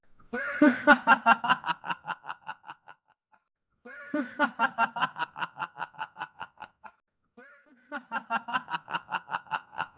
In this work, we introduce SonicMotion, the first end-to-end latent diffusion framework capable of generating FOA audio with explicit control over moving sound sources.
Prompt: "A laughing man slowly moves counter-clockwise from the left to the front"